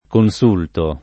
[ kon S2 lto ]